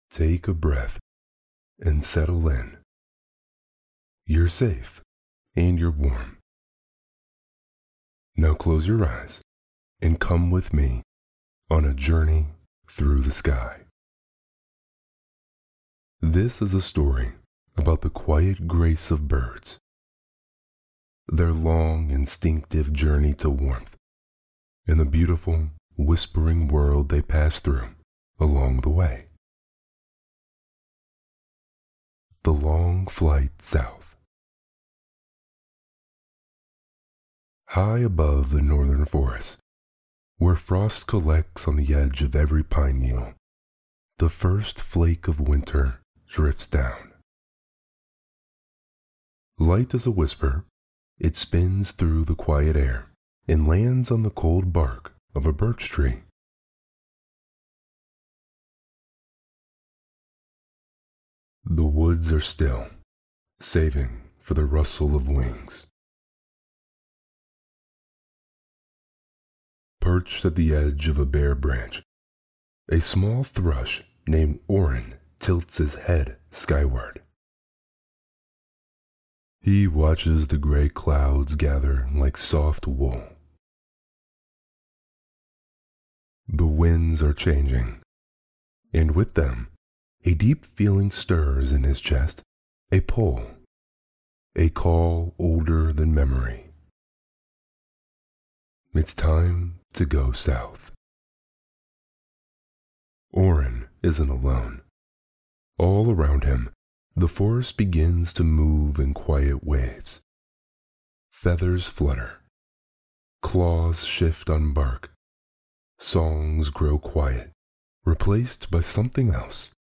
Winnie The Pooh - A.A. Milne (Chapter 2&3) – Dozing Off | Deep Voice ASMR Bedtime Stories – Podcast